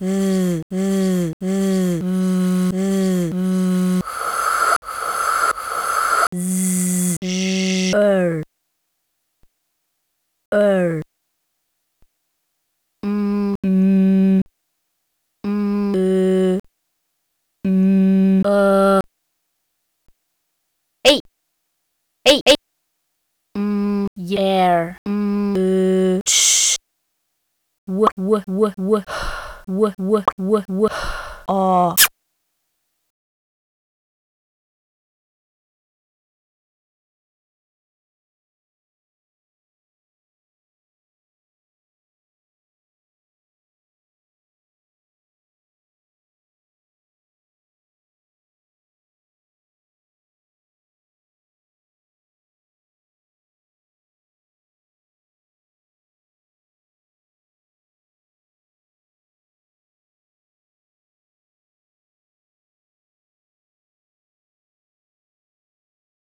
phonetic typewriter Begegnung
Spricht man mit- oder gegeneinander? Erst ganz zum Schluß gibt es mit dem spitzen Kuß eine Antwort.